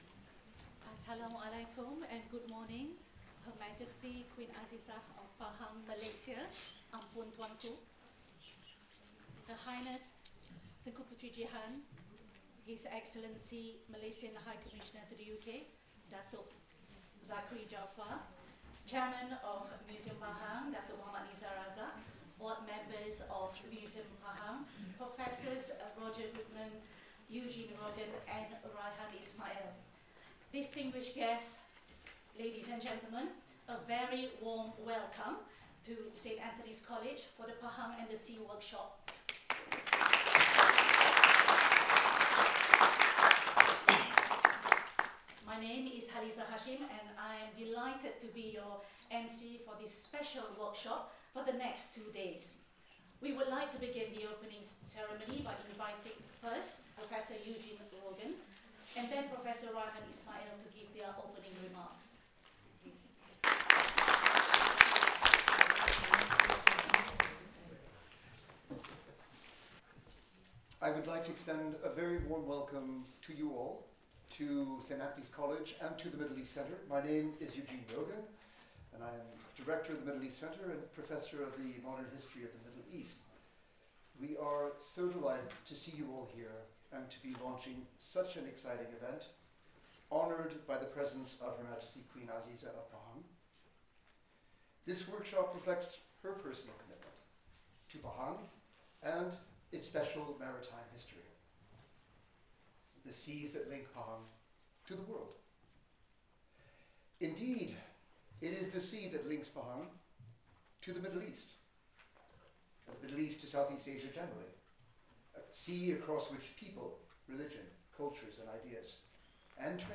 This keynote address by the Queen repositions Pahang as a sovereign maritime kingdom whose historical and legal significance has long been overshadowed by the western-oriented narratives of Melaka and other coastal polities.